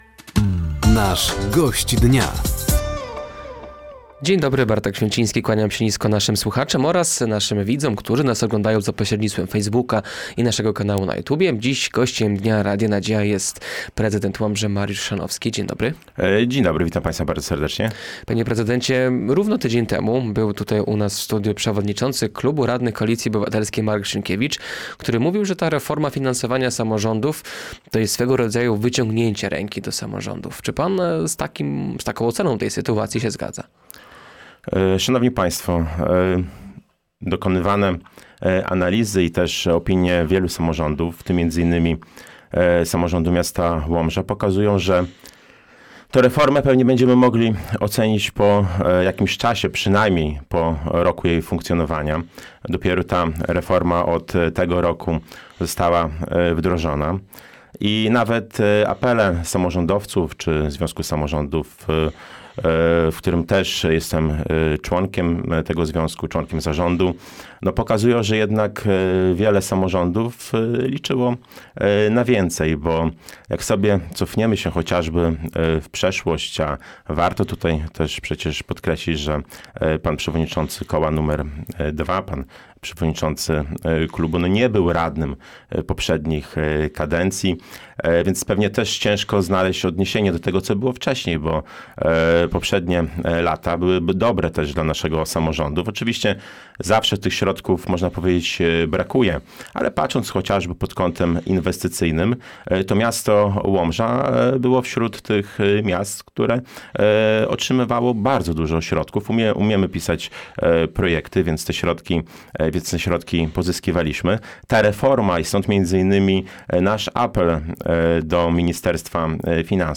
Gościem Dnia Radia Nadzieja był prezydent Łomży Mariusz Chrzanowski. Tematem rozmowy były między innymi finanse miasta, współpraca z przedsiębiorcami, obowiązki w zakresie obrony cywilnej i ochrony ludności, budowa bulwarów i nowa siedziba urzędu stanu cywilnego.